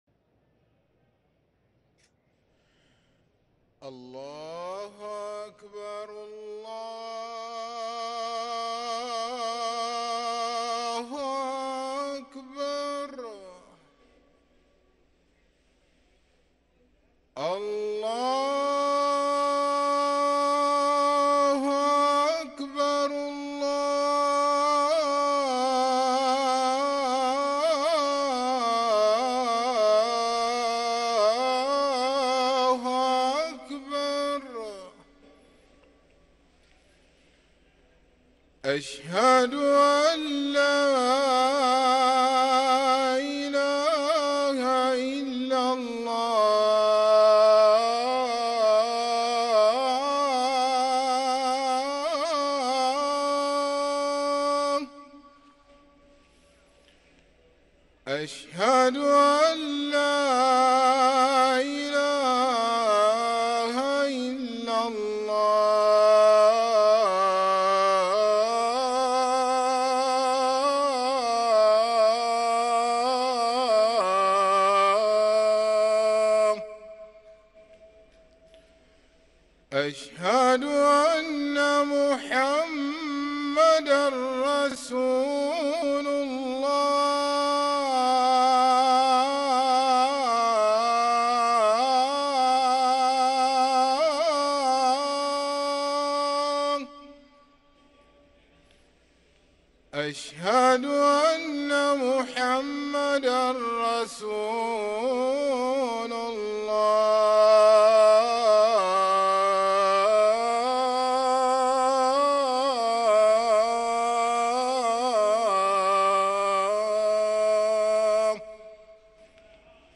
أذان الفجر للمؤذن سعيد فلاته الثلاثاء 14 ربيع الآخر 1444هـ > ١٤٤٤ 🕋 > ركن الأذان 🕋 > المزيد - تلاوات الحرمين